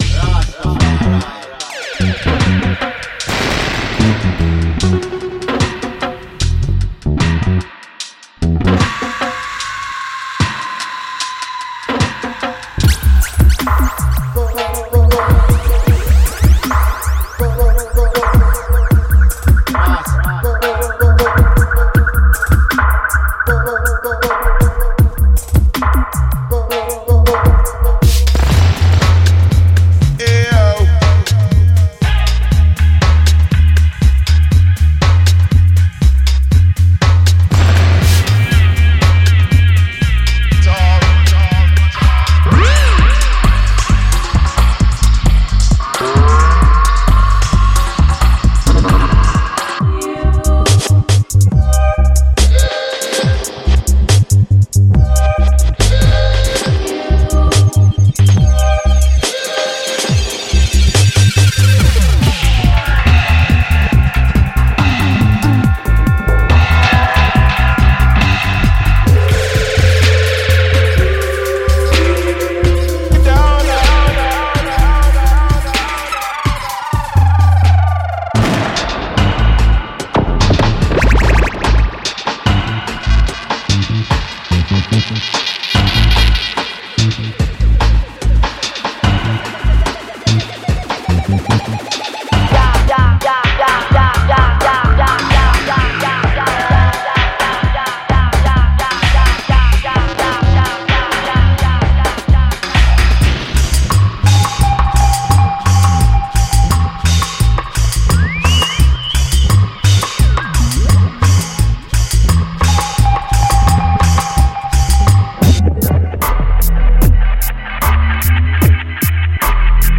このパックには、フルドラムキットグルーブ、トップ（ハイハット＆スネアコンボ）、キック、スネア、ハイハット、そして新しいグルーブを構築するために必要なすべてが含まれています。
（各ループにはドライ、ローファイ、リバーブ、ダブなどのバージョンがあります）
デモサウンドはコチラ↓
Genre:Reggae
1202 Drum Loops
Tempo Ranges: 65-175 BPM